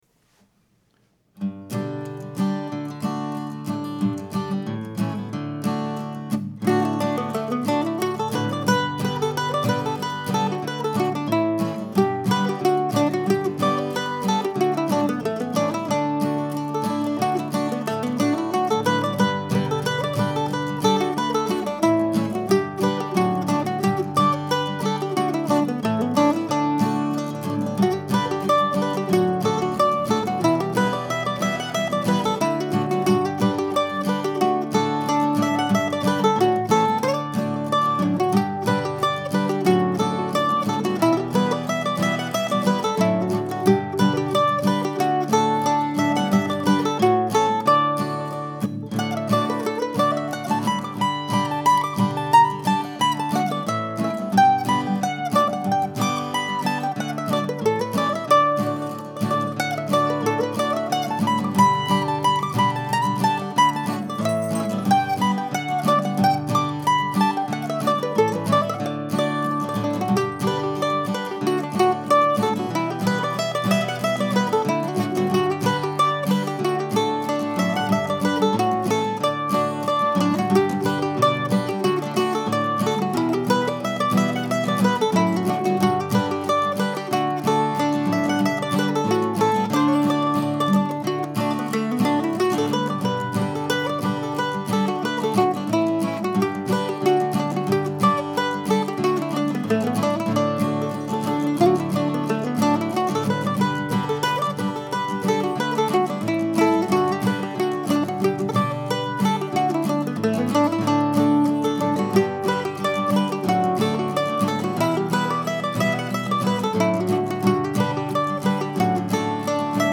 These were both done in bare bones fashion, no harmony parts, no extra instruments. Just guitar chords and mandolin melody, three times through each tune.